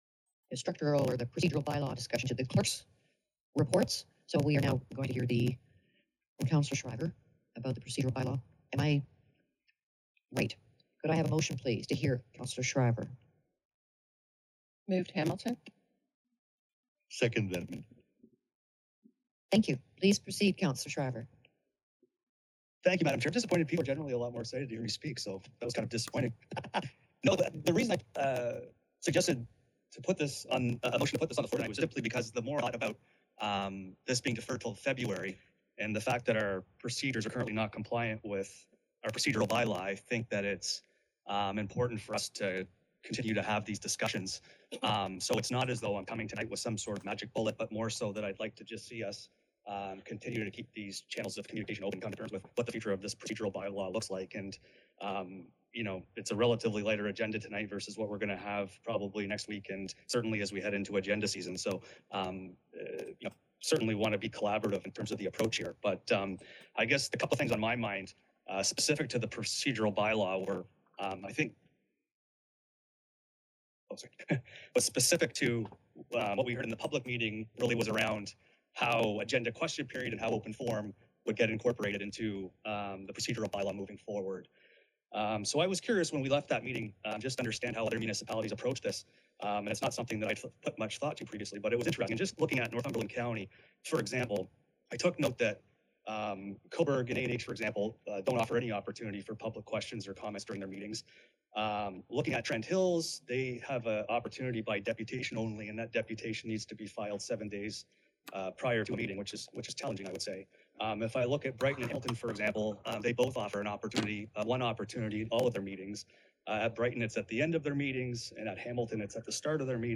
Tensions over the accountability, transparency, and responsiveness of the Cramahe Township council and staff were hotly debated once again at a meeting on Dec. 3.
Councillor Joel Schriver started by suggesting how to handle public questions, which led to a broader debate about making changes.